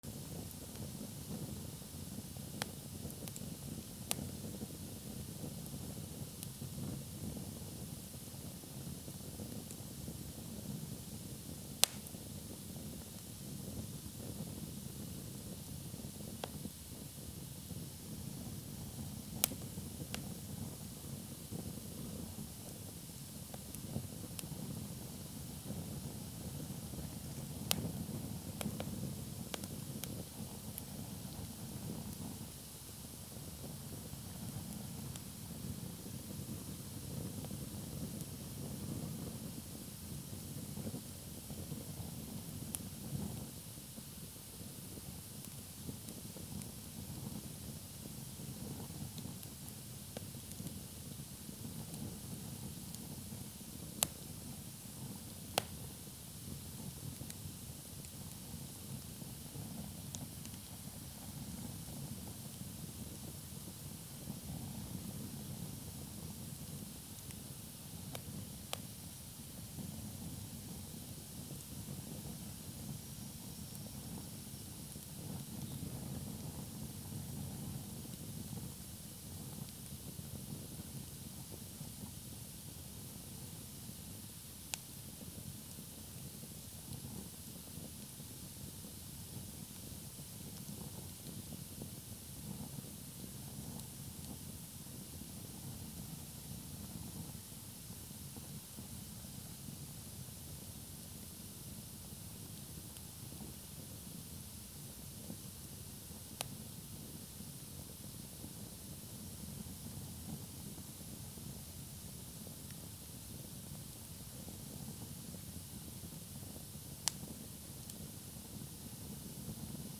/ C｜環境音(人工) / C-42 ｜火を燃やす / 2_D50
26紙を燃やす